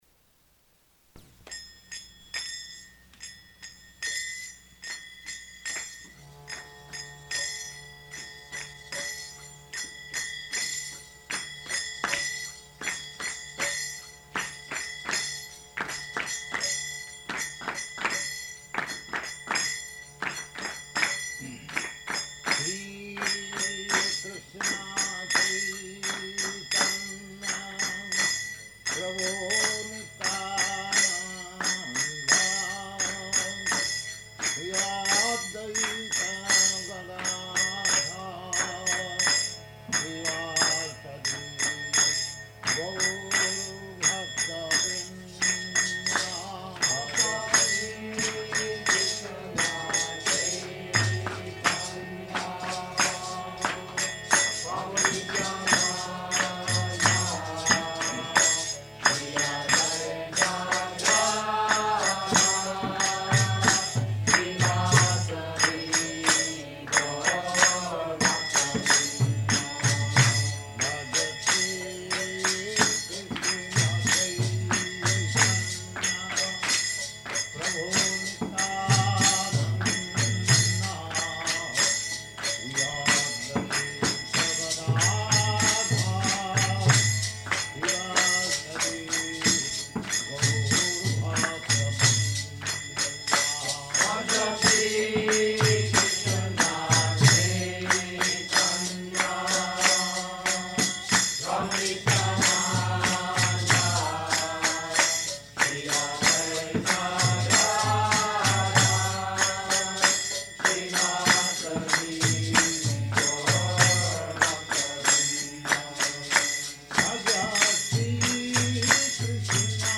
Lecture
Lecture --:-- --:-- Type: Lectures and Addresses Dated: October 9th 1968 Location: Seattle Audio file: 681009LE-SEATTLE.mp3 Prabhupāda: [ kīrtana ] [ prema-dhvani ] Thank you very much.